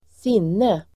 Uttal: [²s'in:e]